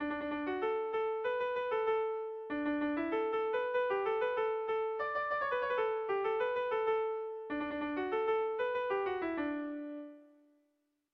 Sentimenduzkoa
Zortziko txikia (hg) / Lau puntuko txikia (ip)
A1A2A3A4